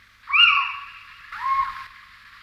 Chevêche d'Athéna
Athene noctua
cheveche.mp3